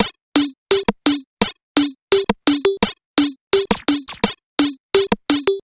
Percussion 11.wav